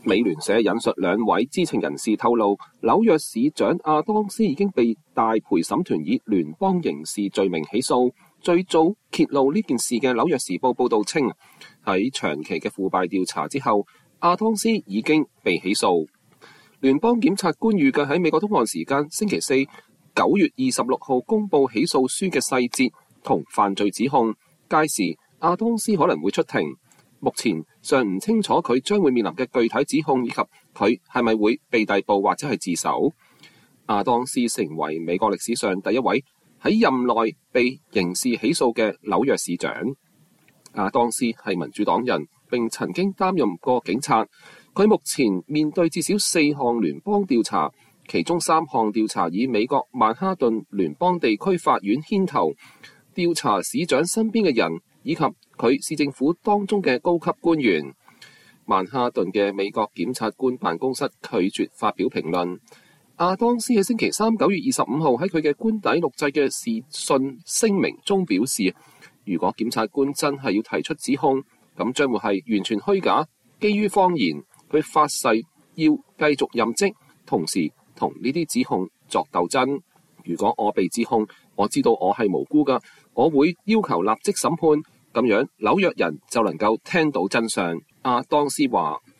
紐約市長亞當斯發表影片聲稱檢察官的指控是虛假的，他將竭盡全力和精神與這些與這些指控鬥爭。